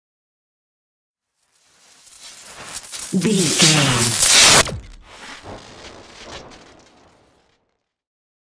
Appear sci fi electricity
Sound Effects
futuristic
high tech
tension
whoosh
sci fi